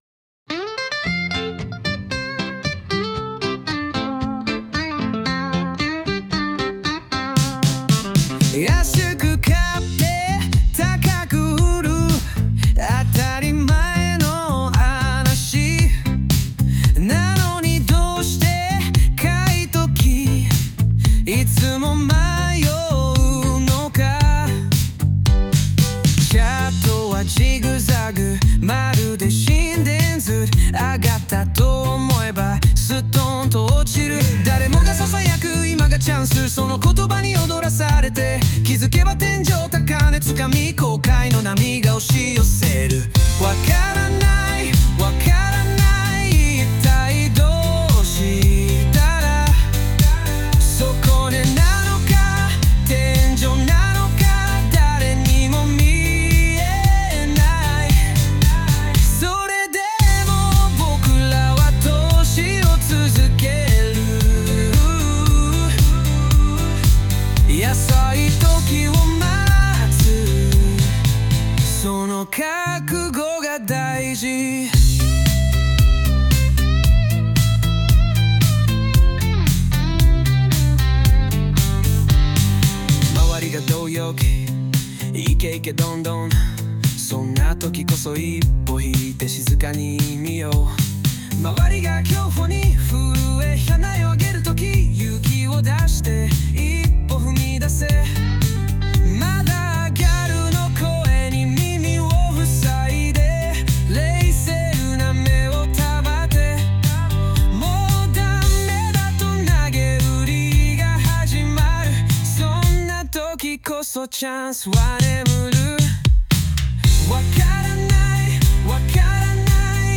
暇に任せてsunoAIで投資の曲を作ってみました。